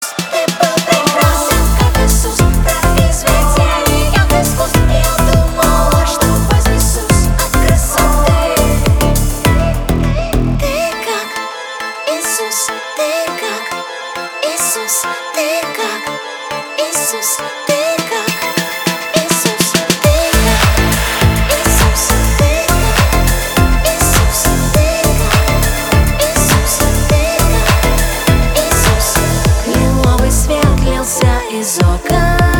• Качество: 320, Stereo
поп
красивый женский голос
орган
церковные